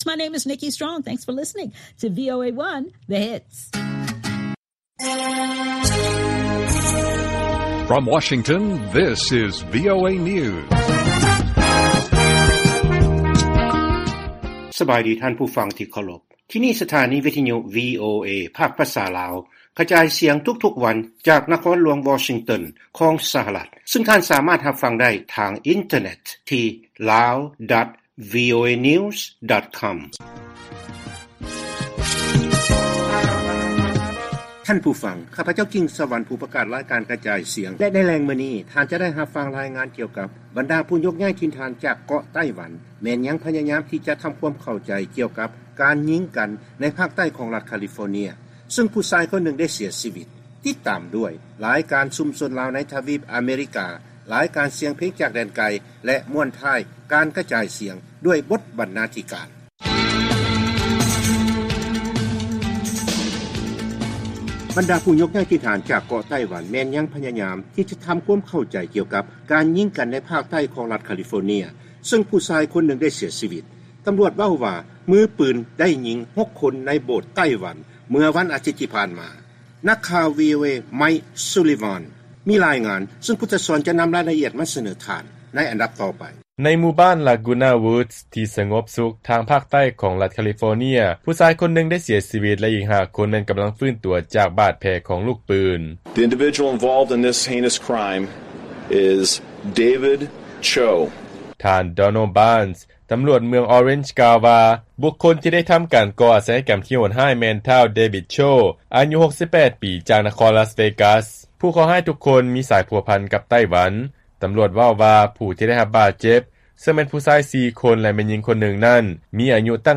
ລາຍການກະຈາຍສຽງຂອງວີໂອເອລາວ